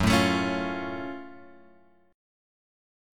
F#mM7 chord {2 4 3 2 2 2} chord